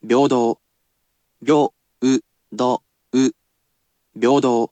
I personally sound out each word or phrase aloud for you to repeat as many times as you wish, and you can ask me to say it as many times as you wish.